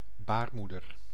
Ääntäminen
Synonyymit matrice Ääntäminen France: IPA: /y.te.ʁys/ Haettu sana löytyi näillä lähdekielillä: ranska Käännös Konteksti Ääninäyte Substantiivit 1. baarmoeder {f} anatomia 2. uterus anatomia Suku: m .